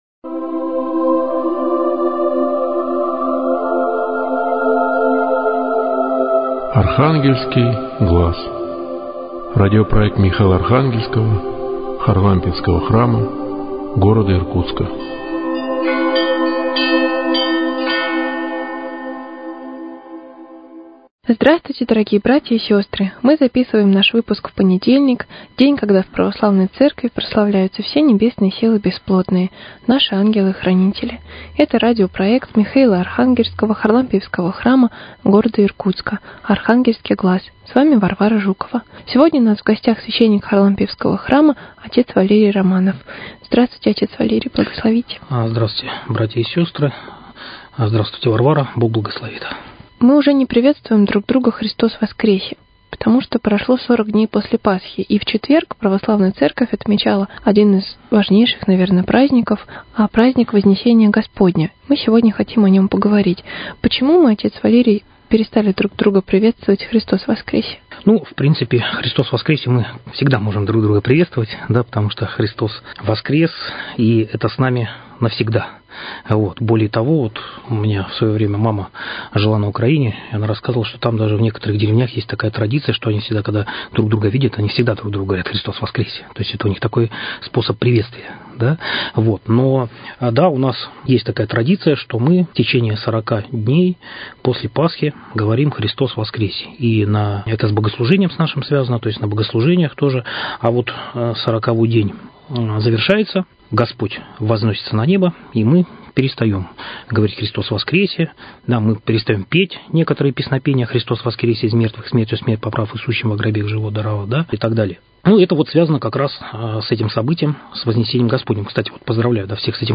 Передача из цикла – проекта Михаило – Архангельского Харлампиевского храма рассказывает о празднике Вознесение Господне. Беседа